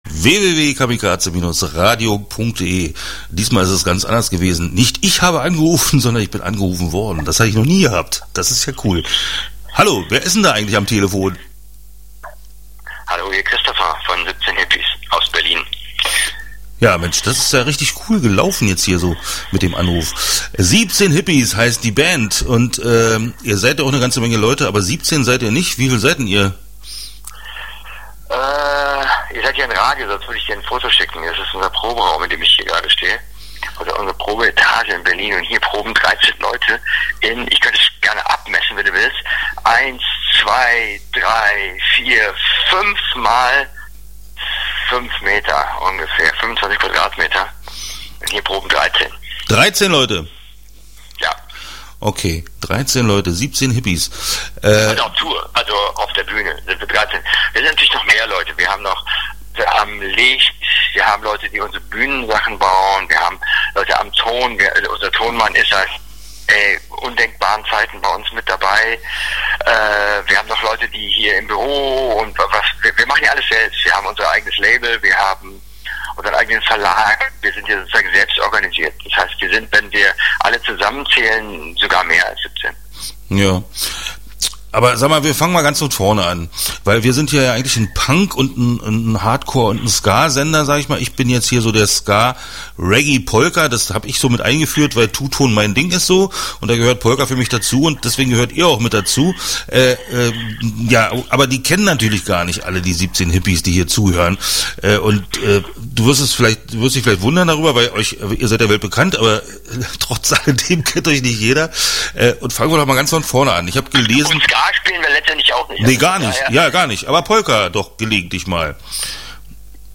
Interview Teil I (17:35)